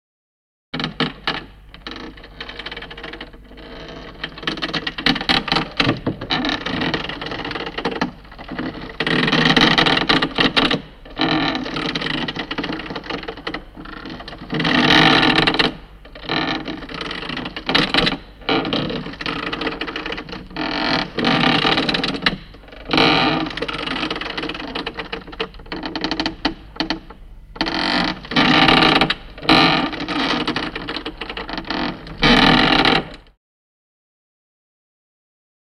Sailing Ship; Creaking; Ship Creaking.